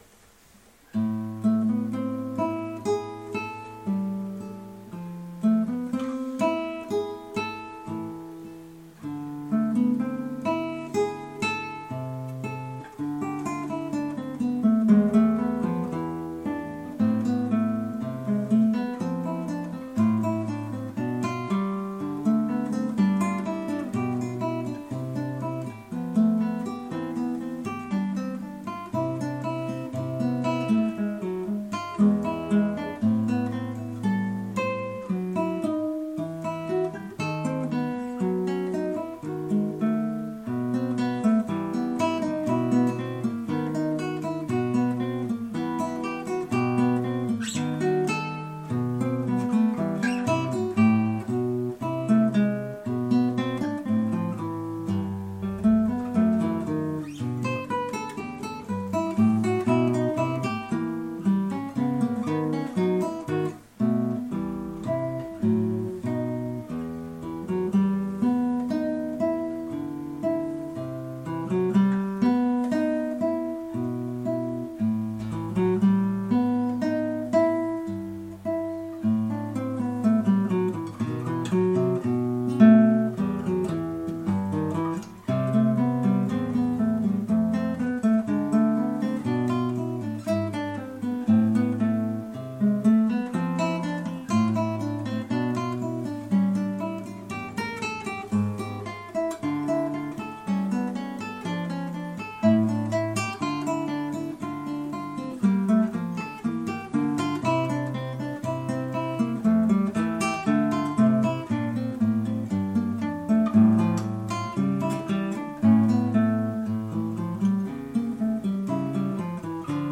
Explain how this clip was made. Cracks, buzz and other with Focusrite Clarett 4 Pre and thunderbolt on Mac.